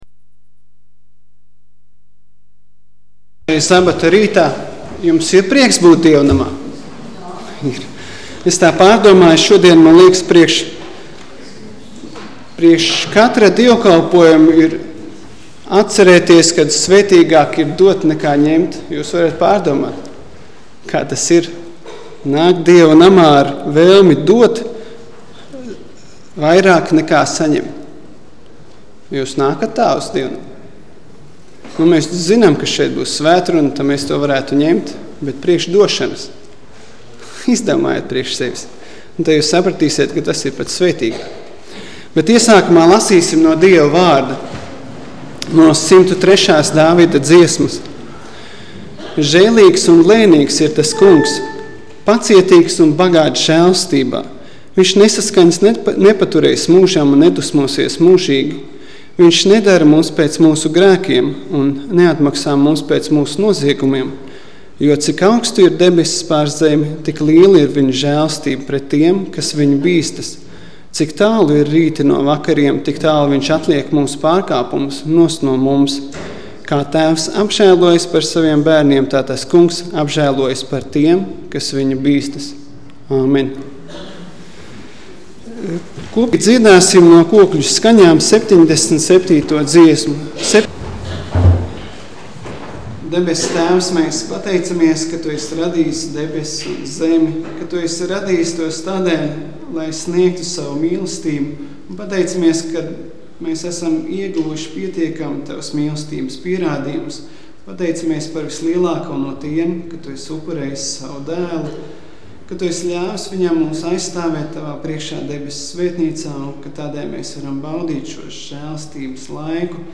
Svētrunas